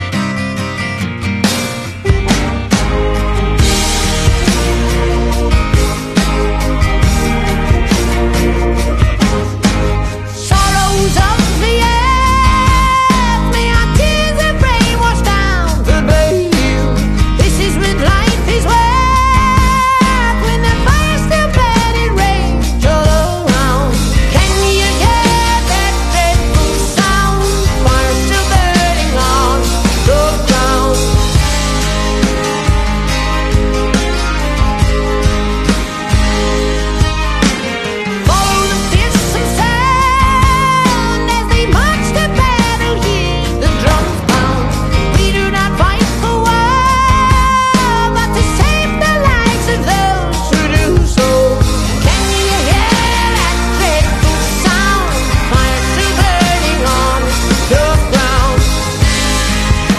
The crowd did not dissapoint.